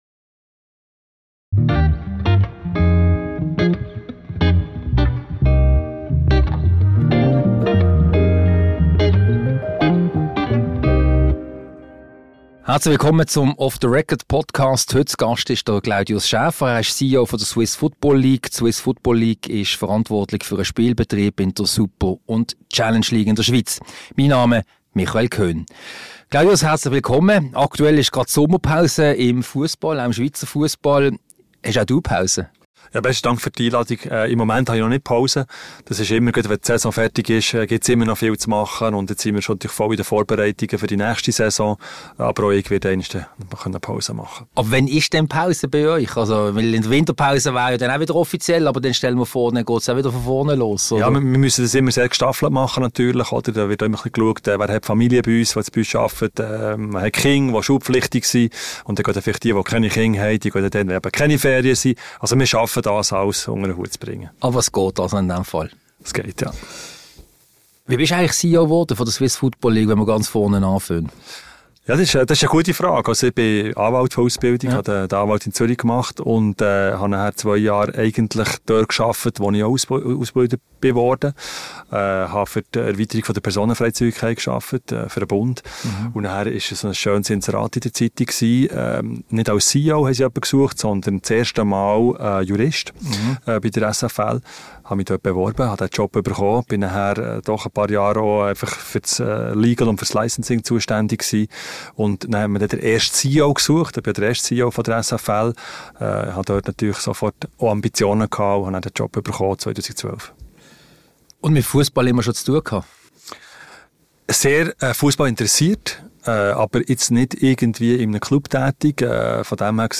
Ein Gespräch über TV-Rechte, den neuen Modus der Swiss Football League und Fussball zwischen Weihnachten und Neujahr.